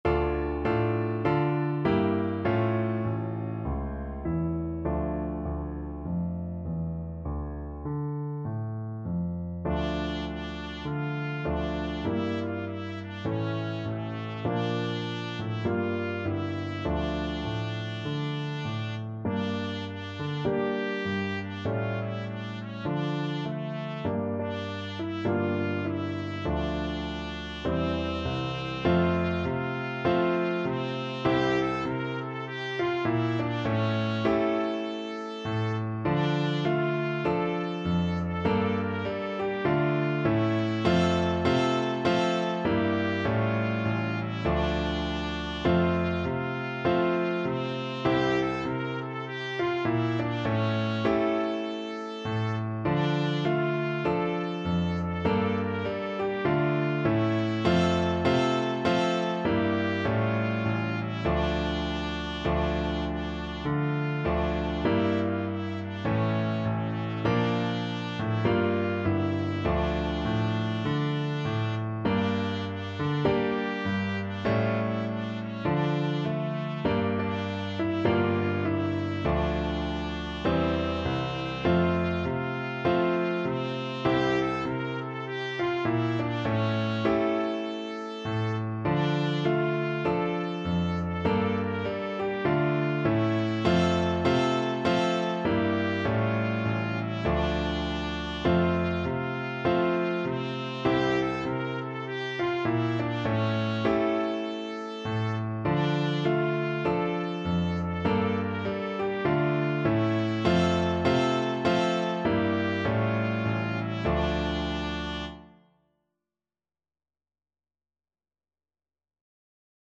Oi u luzi chervona kalyna (Ой, у лузі червона калина) Free Sheet music for Trumpet
Trumpet version